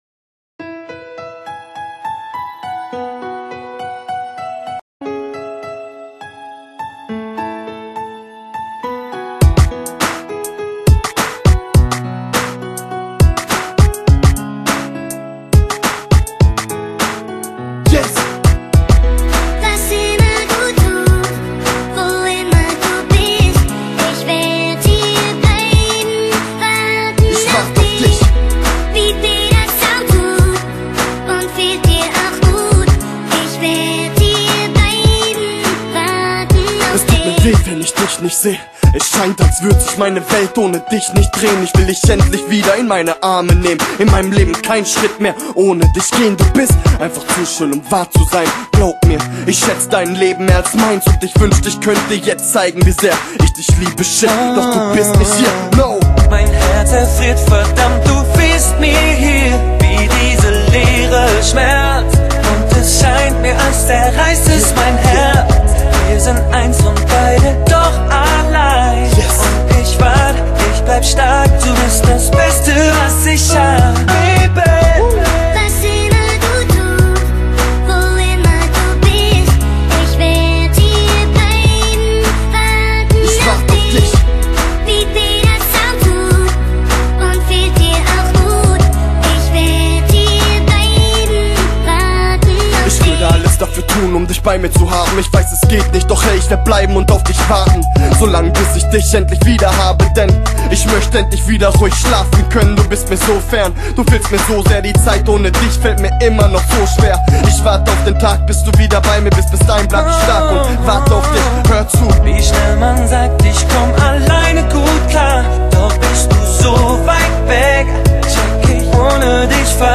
使用电子舞曲声效，多样元素结合的曲风使歌曲充满动感~~